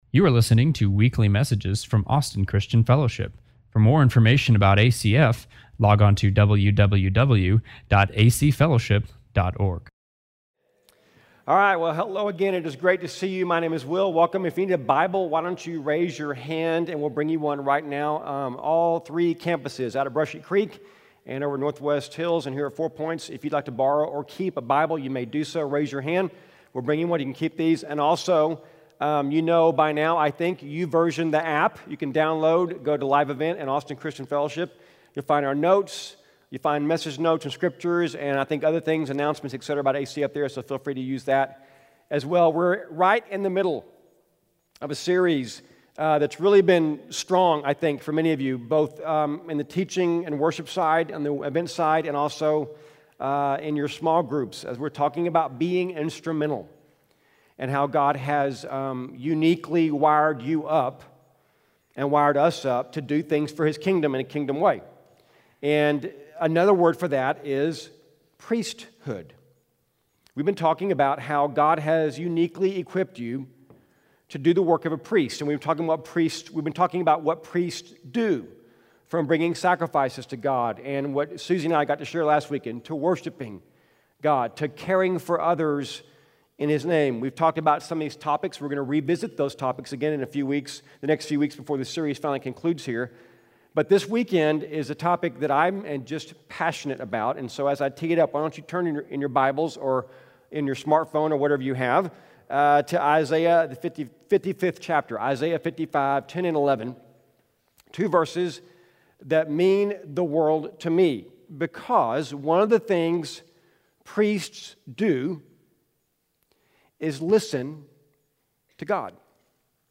I was privileged tho speak this weekend at ACF on the subject of hearing God through the Bible.